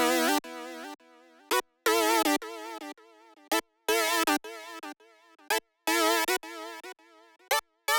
34 5th Synth PT2.wav